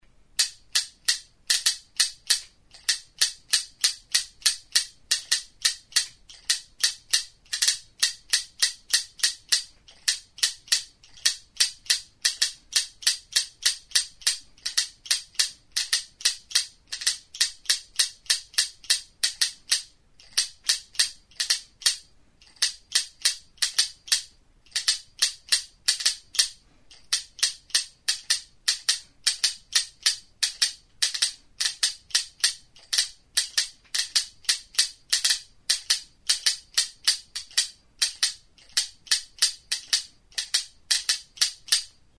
Grabado con este instrumento.
Instrumentos musicales: TXEPETXA Clasificación: Idiófonos -> Golpeados -> Indirectamente
Descripción: Banbuzko bi tuturekin egindako txepetxa da.